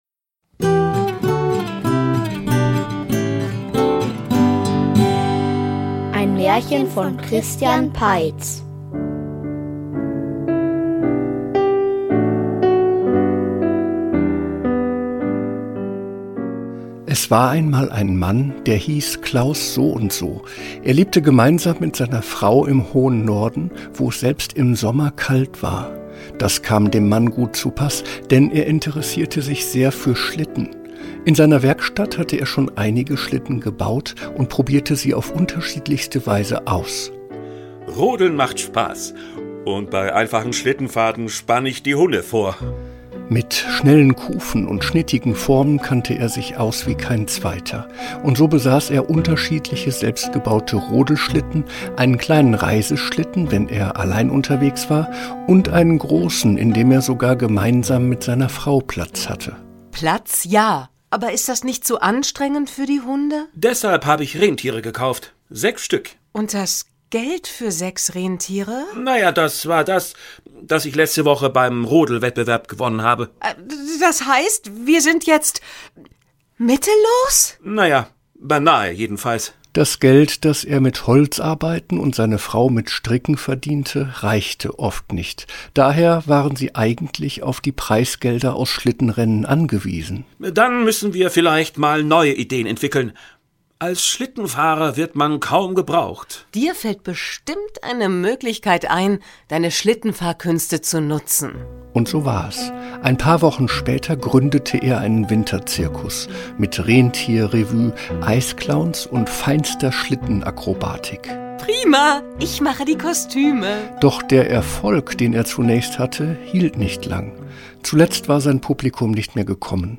Ein Hörspiel über Moorlöcher & Kräutertee - nicht nur für Erwachsene Inhalt: Zwei Brüder mit besonderen Fähigkeiten sind auf dem langen Weg nach Hause. Da verirren sie sich in einem nebligen Moor.